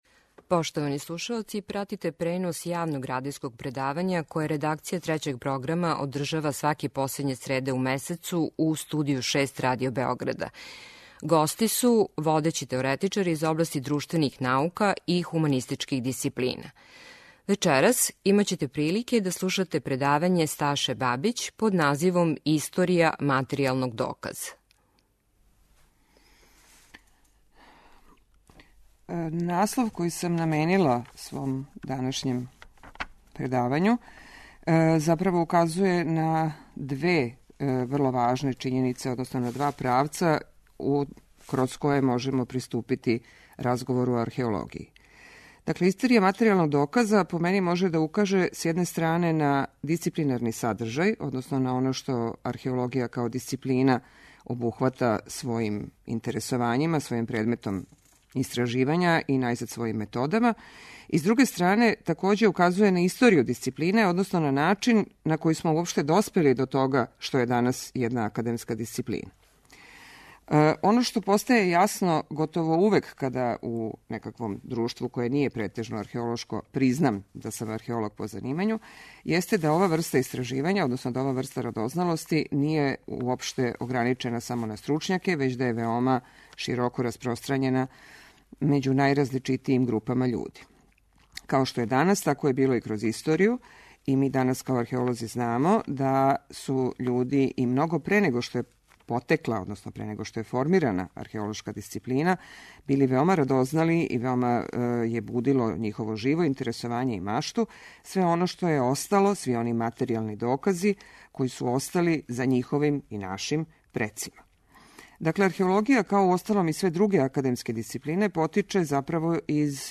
Директан пренос из Студија 6
преузми : 18.38 MB Радијска предавања, Дијалози Autor: Трећи програм Из Студија 6 директно преносимо јавна радијска предавања.